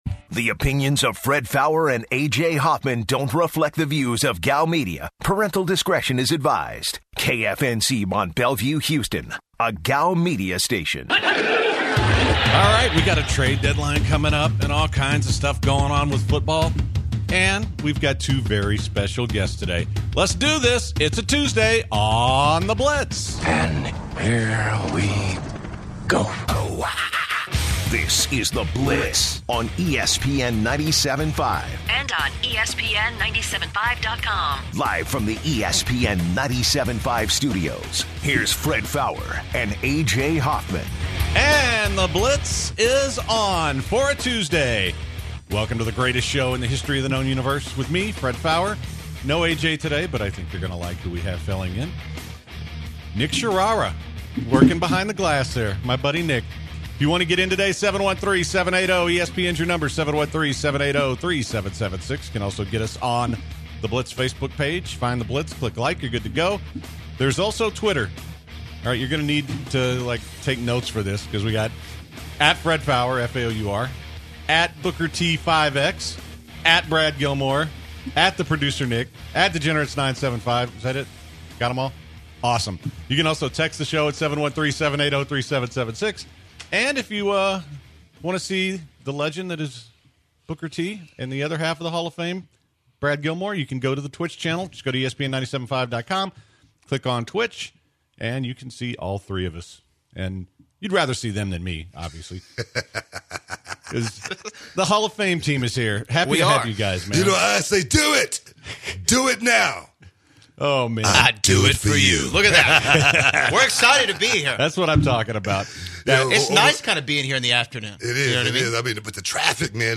Booker T explains his history in wrestling as many callers call to ask him questions.